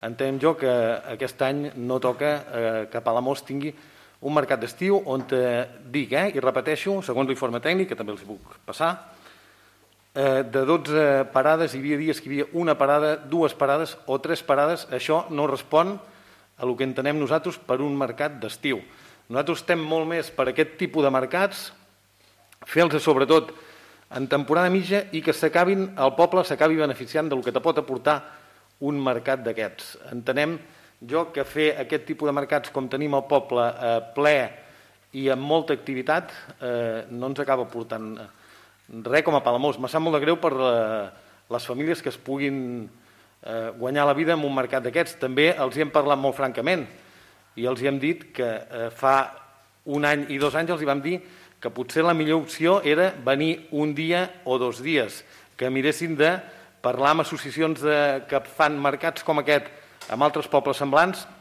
Ho va explicar el regidor al Ple municipal, tal com informa la ràdio municipal.